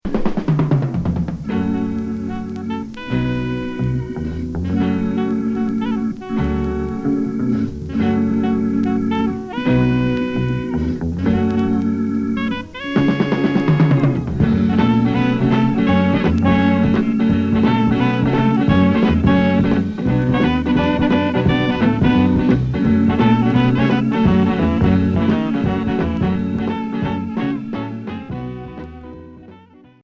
Groove pop & Variétes